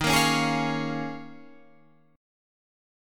D#7sus2sus4 chord